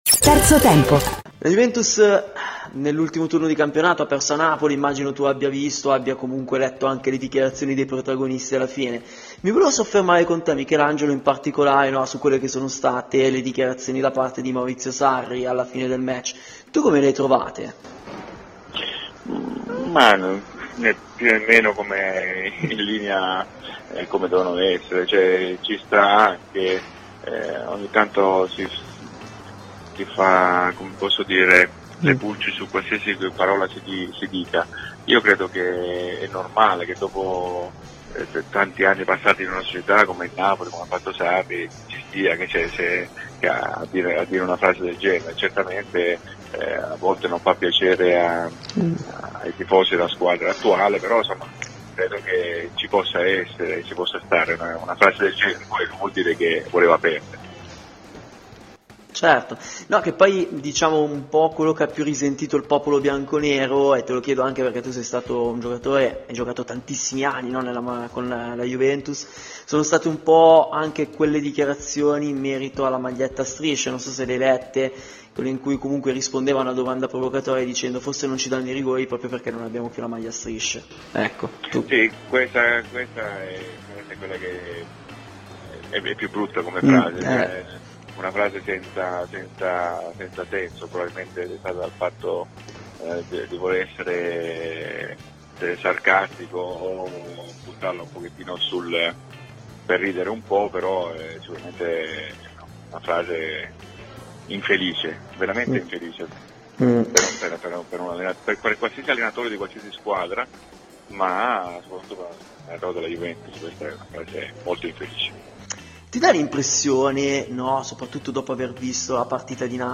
Ai microfoni di Radio Bianconera, nel corso di ‘Terzo Tempo’, è intervenuto l’ex Juventus Michelangelo Rampulla: “Le parole di Sarri a fine gara?